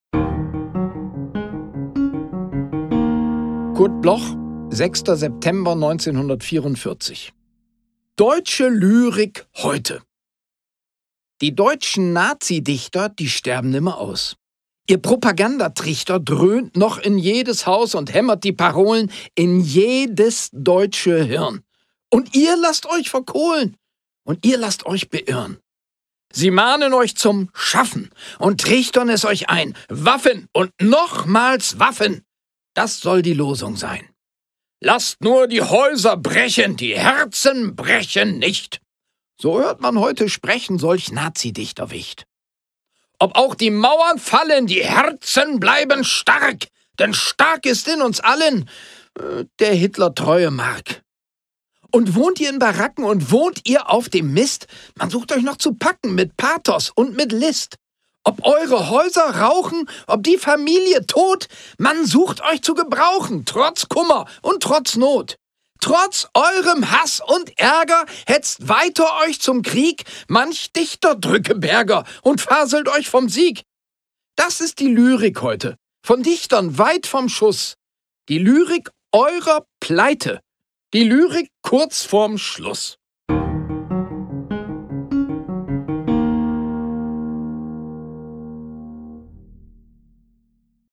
Aufnahme: Tonstudio Schloss Seefeld · Bearbeitung: Kristen & Schmidt, Wiesbaden
Wigald_Boning-Deutsche-Lyrik-heute_mit-Musik_raw.m4a